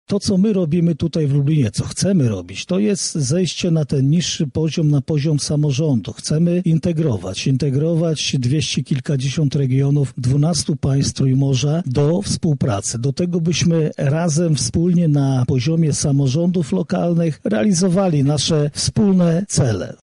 -mówi Jarosław Stawiarski, marszałek województwa lubelskiego.